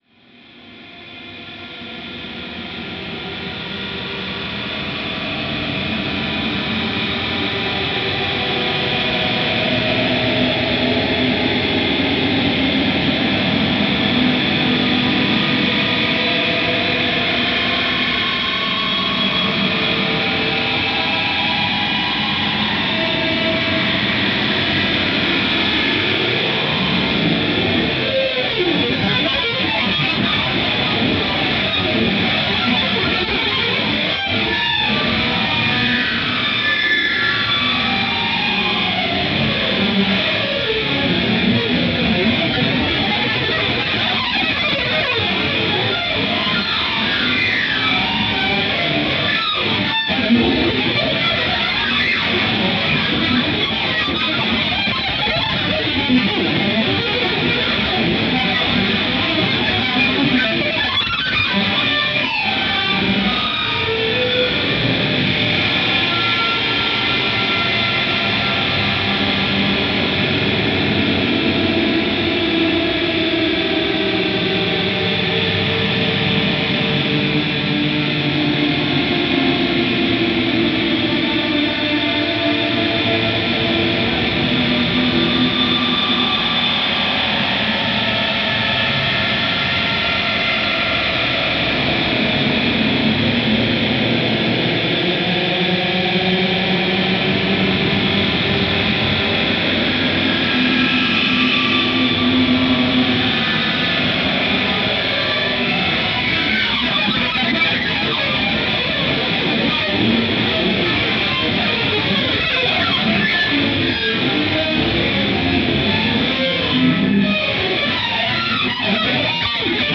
ギター愛好家の方々にはもちろん、現代音楽、先端的テクノ、実験音楽をお好きな方々にもお薦めのアルバムです。